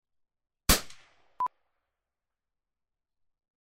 308 Bolt action Rifle, Single gun Shot 4 (sound fx)
308 bolt action rifle gun shot. Bang, Snap, crack, Echo, reverberant sound. Weapon Sound Effects, Gun shots
308_RifleShots-04_plip.mp3